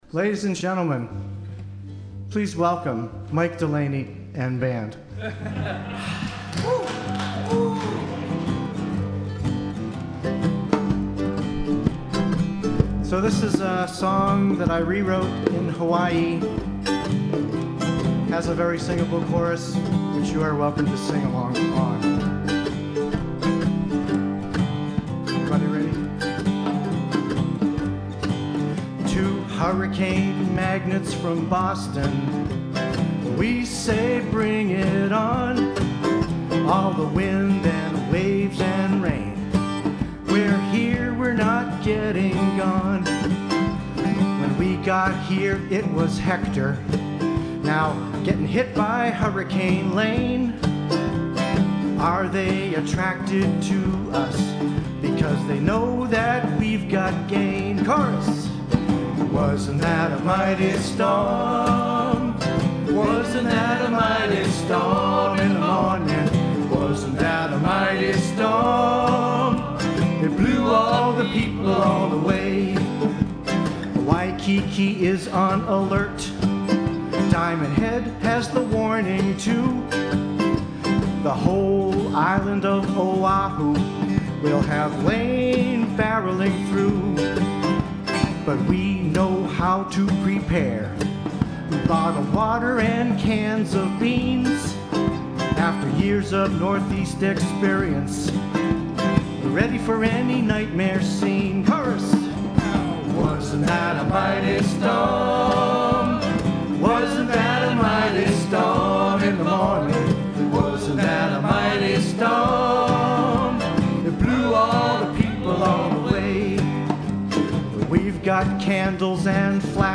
parody for Hawaii Hurricanes.
audio of the performance at ROM